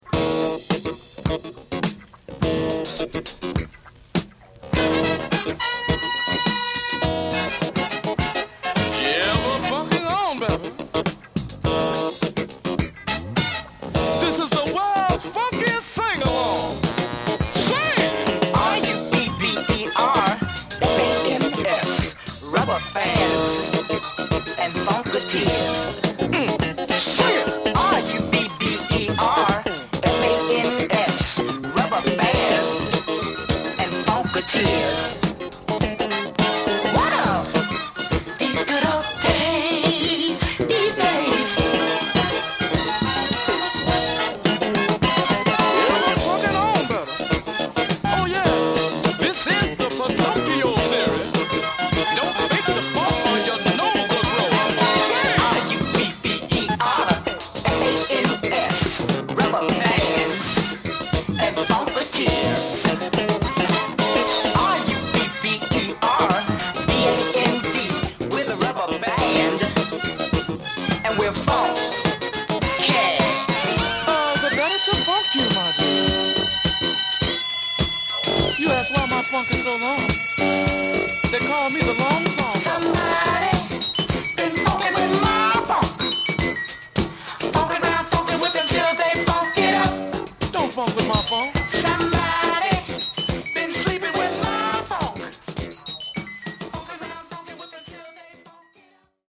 Eclectic, P funk meets sunshine disco goodness.